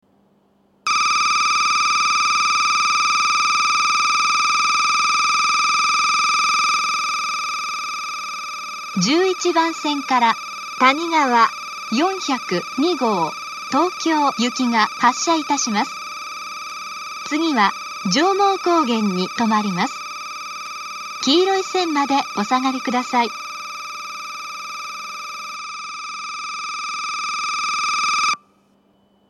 １１番線発車ベル たにがわ４０２号東京行の放送です。